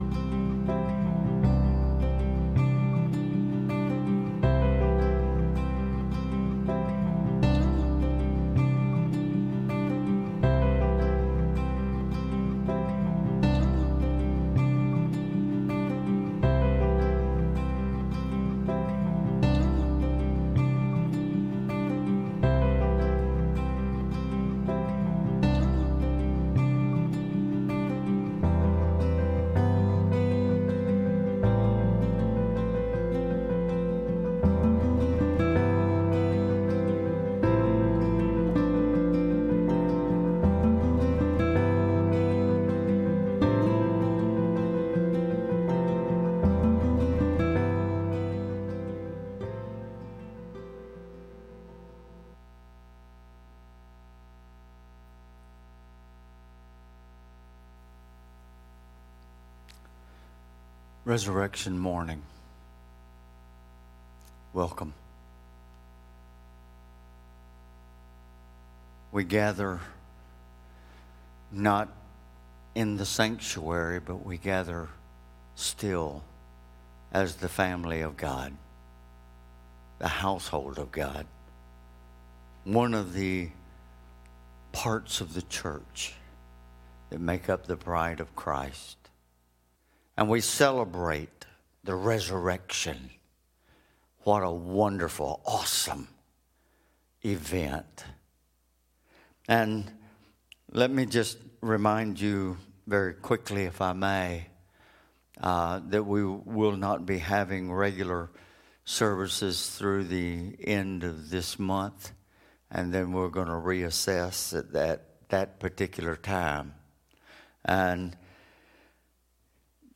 Sermons | First Assembly of God Rock Hill